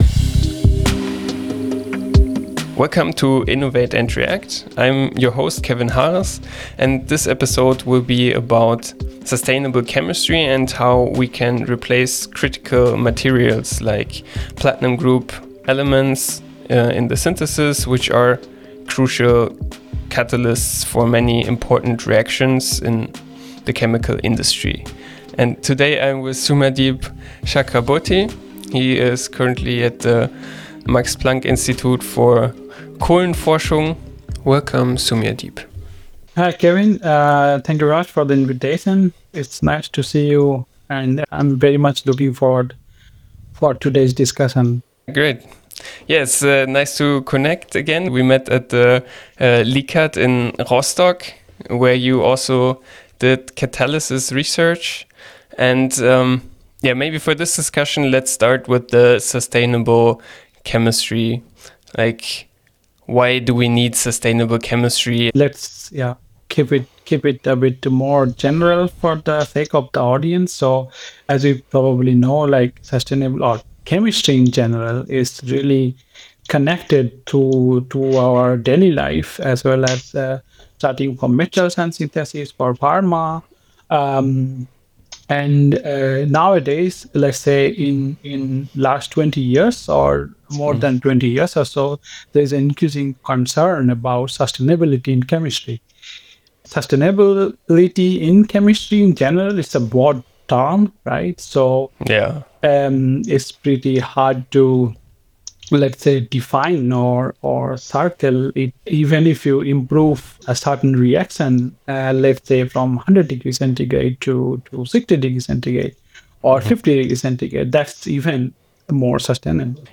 The conversation also touches on collaboration between academia and industry and the impact of high-throughput screening and AI on future catalysis research.